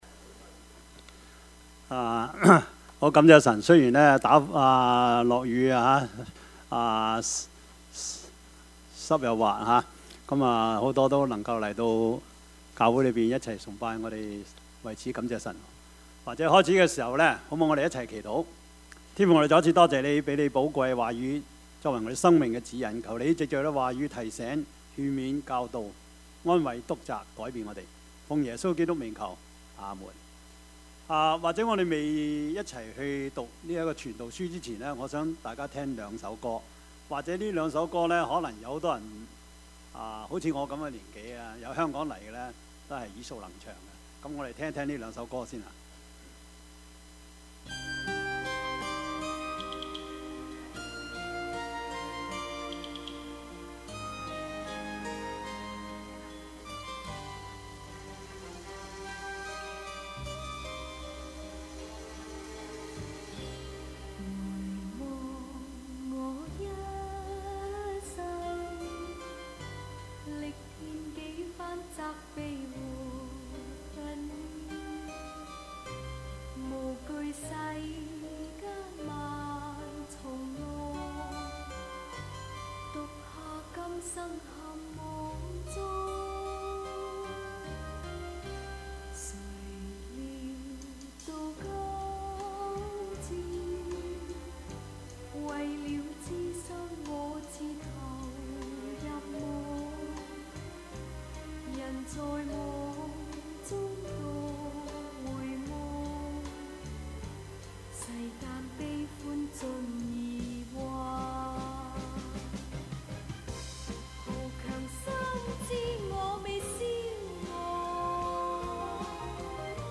Service Type: 主日崇拜
Topics: 主日證道 « 新年願望 挪亞–異象人生 »